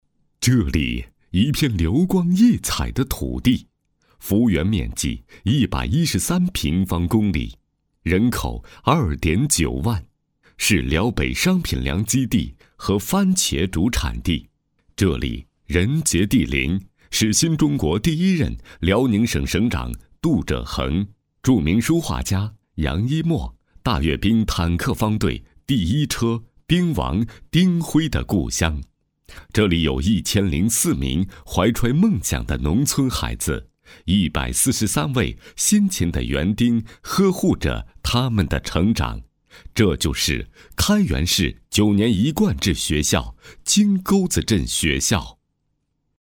成熟稳重 学校专题
优质男播音，擅长专题片，记录片、专题讲述等不同题材。